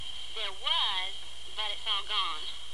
下列紅色部份為省音，已省去不唸；而字串連結為連音。